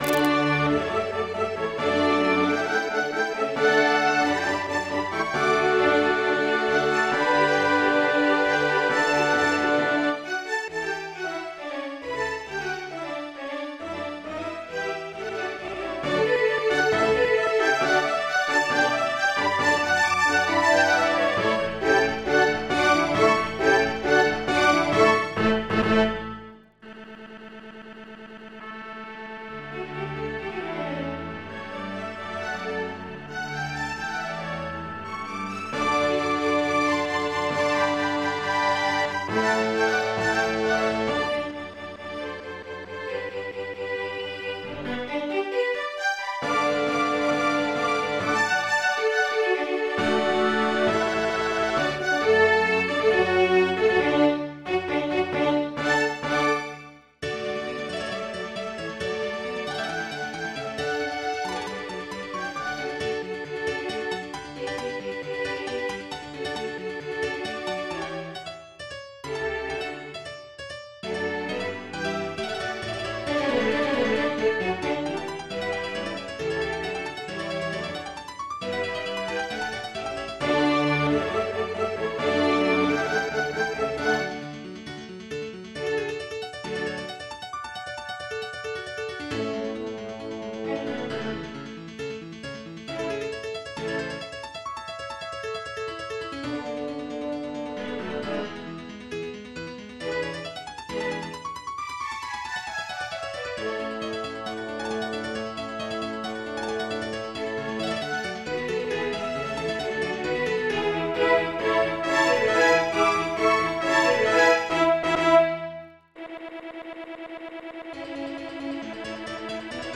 MIDI 104.09 KB MP3 (Converted) 7.42 MB MIDI-XML Sheet Music